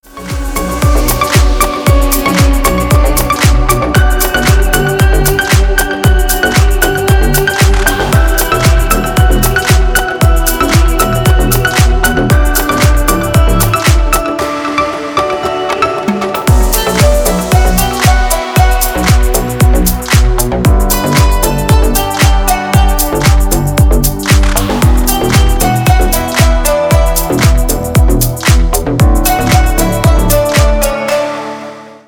• Качество: Хорошее
• Категория: Рингтоны